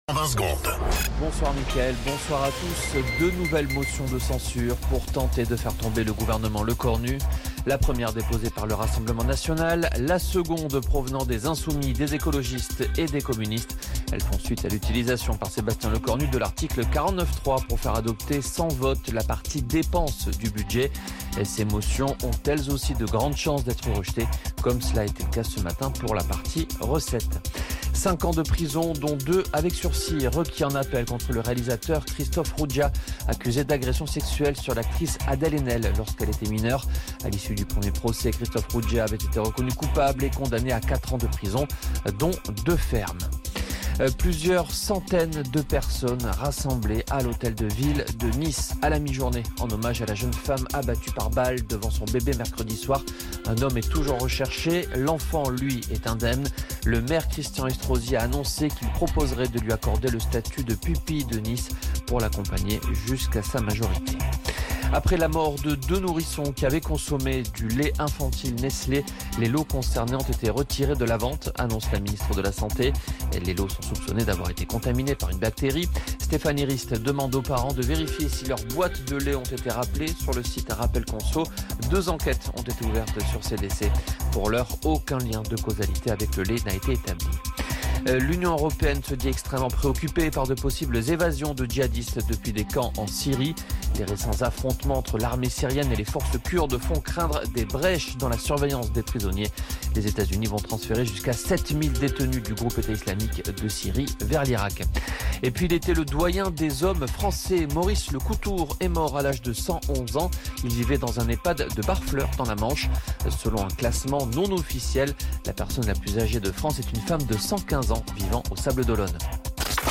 Flash Info National 23 Janvier 2026 Du 23/01/2026 à 17h10 .